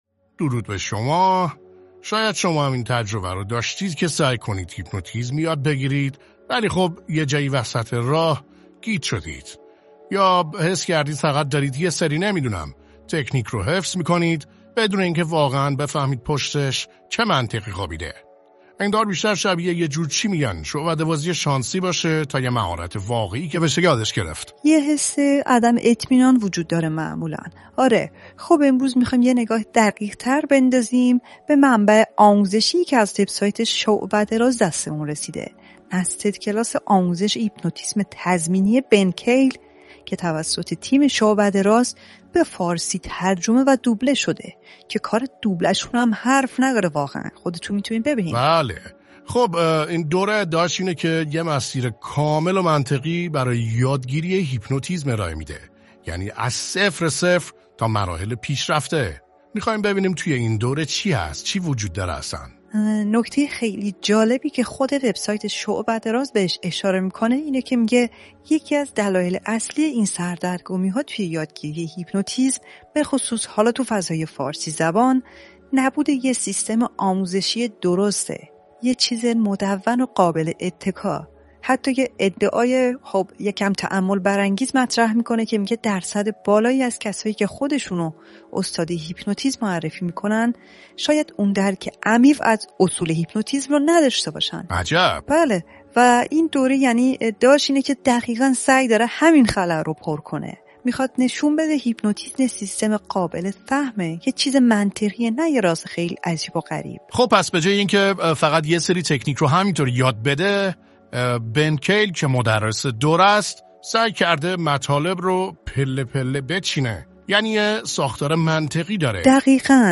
آموزش هیپنوتیزم تضمینی با دوبله فارسی
توضیحات مدرس مسترکلاس آموزش هیپنوتیزم